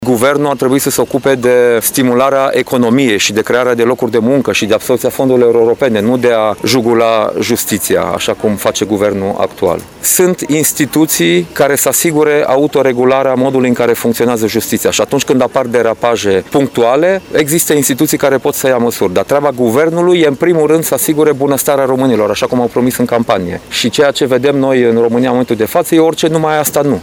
Prezent la Suceava la o întâlnire cu membrii și simpatizanții, președintele Partidului Libertate, Unitate și Solidaritate DACIAN CIOLOȘ a declarat că Guvernul PSD – ALDE continuă tentativele de a politiza justiția și de a intimida magistrații.